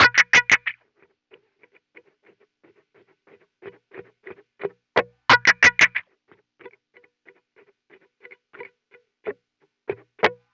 CHAKACHAKA.wav